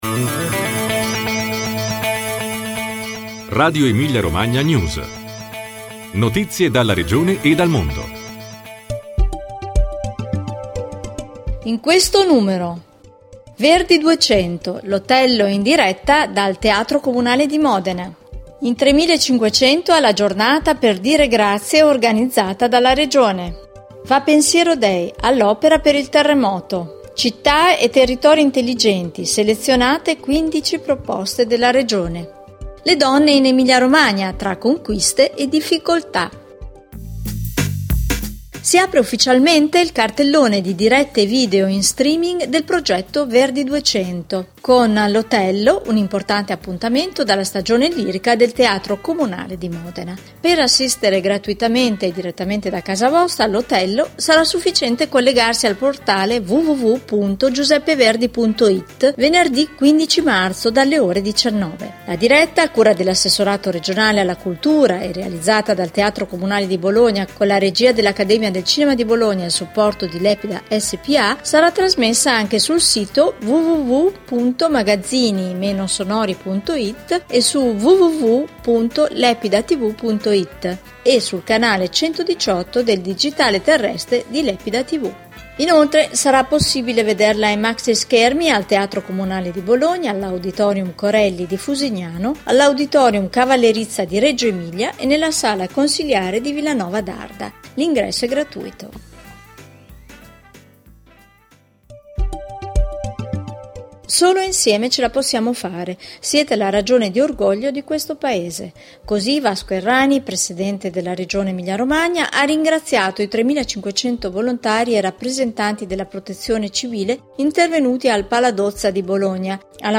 Notizie dalla Regione e dal mondo